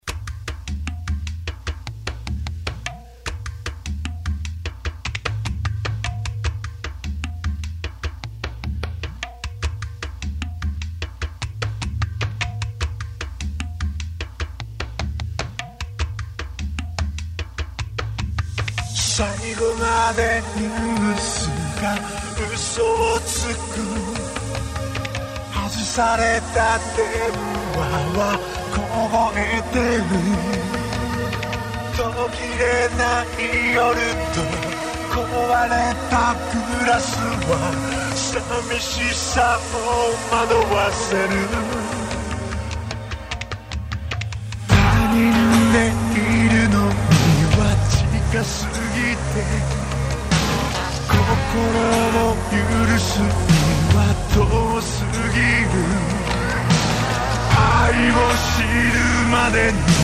結果： だいぶイイ感じではあるが，ワウフラがまだ許容範囲とは言えない → チョット失敗気味